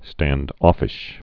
(stănd-ôfĭsh, -ŏfĭsh)